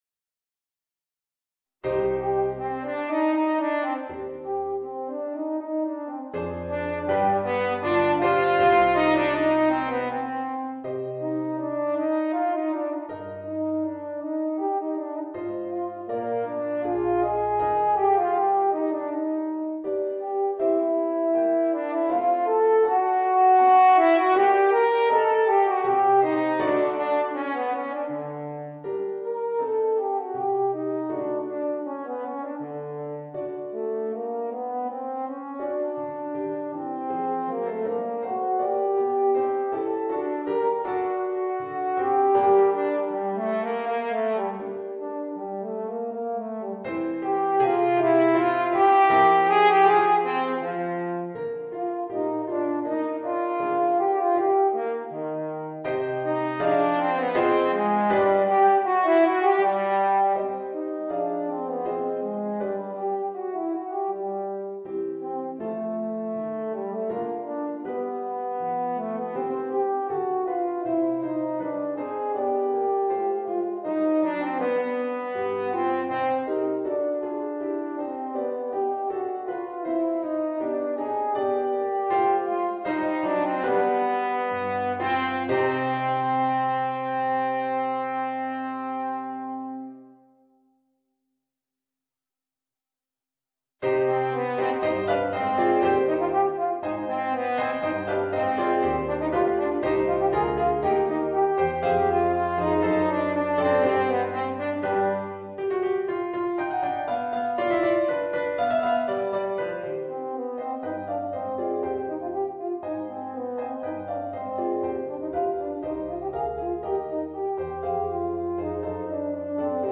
Voicing: Eb Horn and Piano